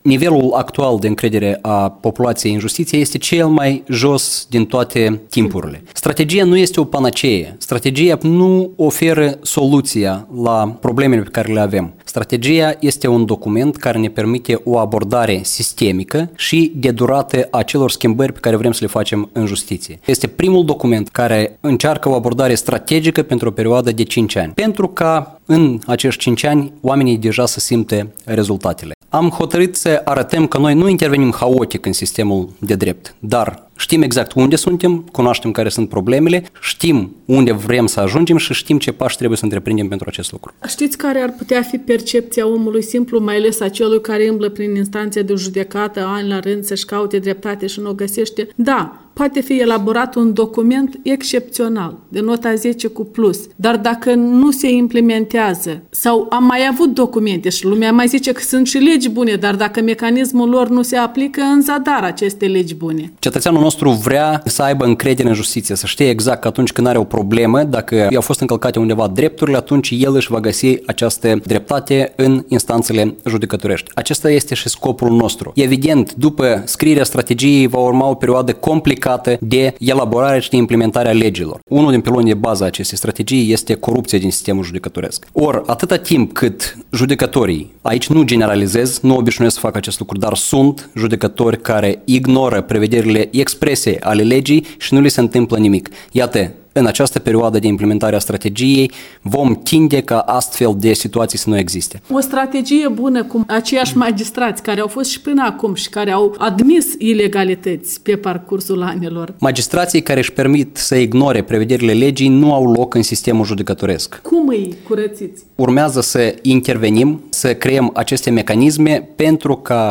Un interviu cu Oleg Efrim